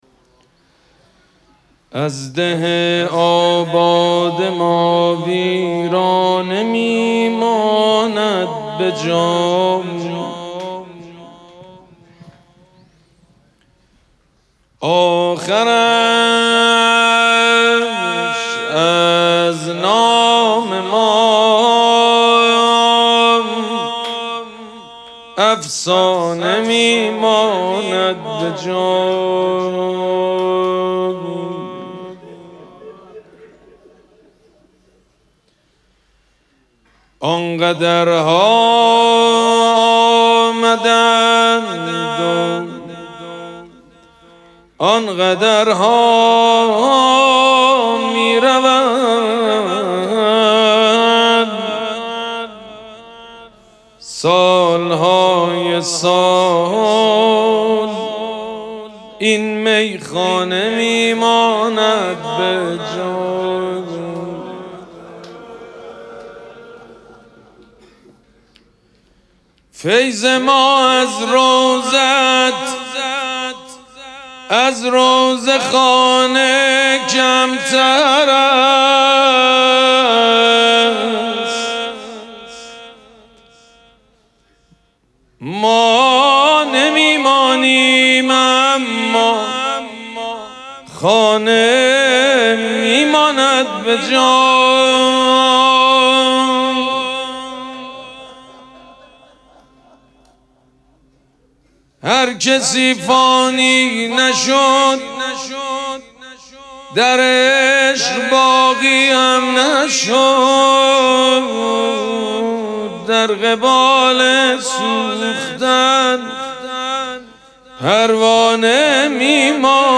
مراسم عزاداری شام شهادت حضرت زینب سلام‌الله‌علیها
حسینیه ریحانه الحسین سلام الله علیها
مناجات